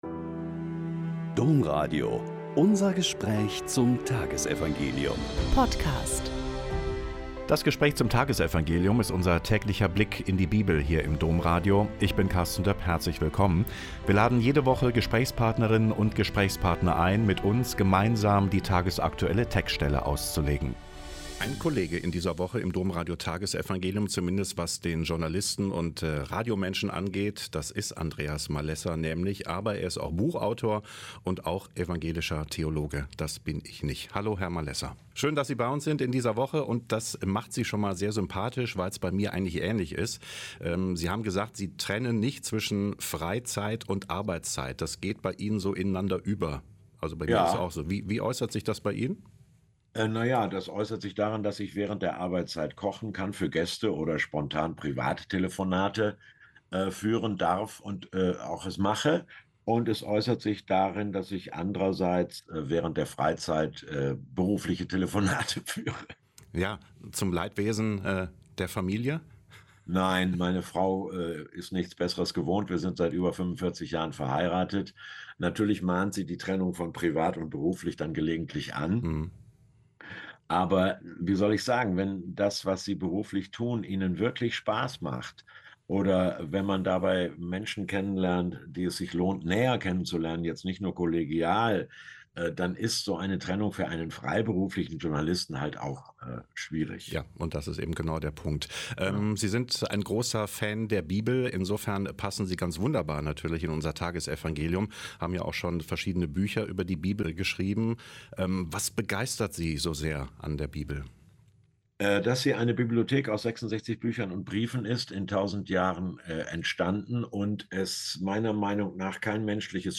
Lk 14,12-14 - Gespräch